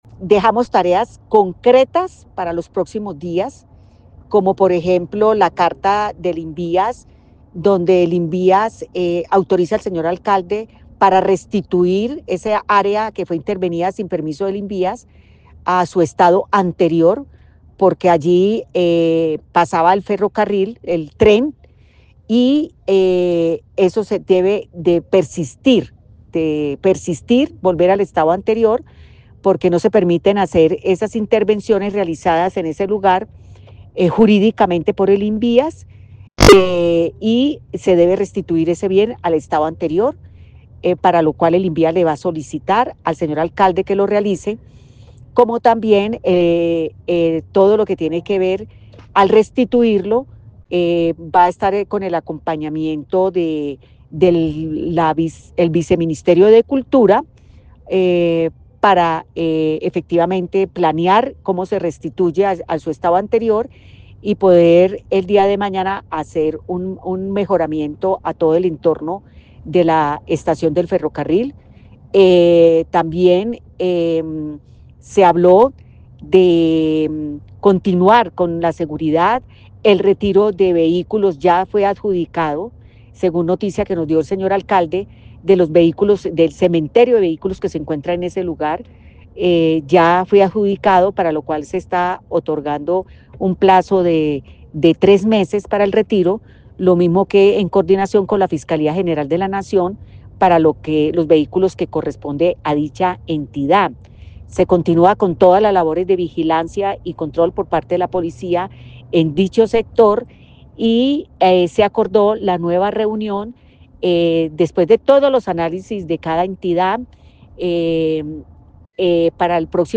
Piedad Correal, Representante a la Cámara del Quindío